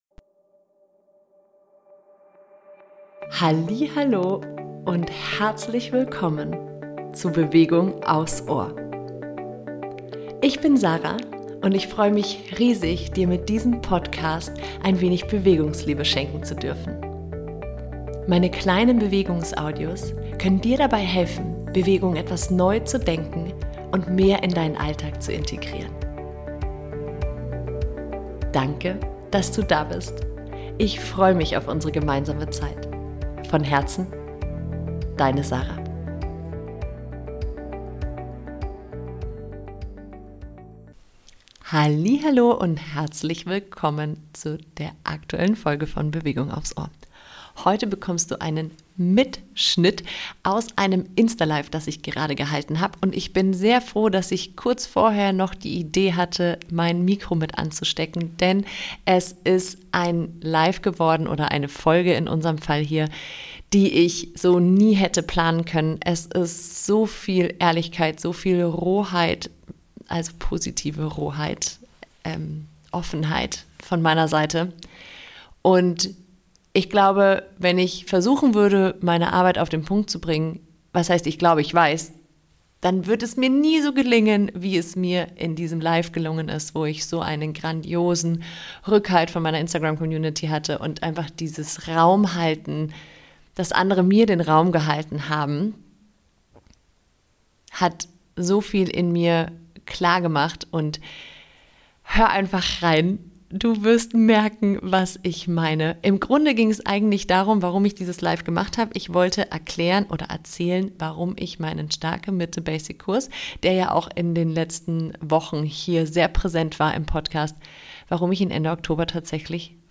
Der Mitschnitt eines Instagram Lives, das zu Tränen gerührt hat – mich selbst eingeschlossen – und in dem sich innerlich einiges neu sortiert hat.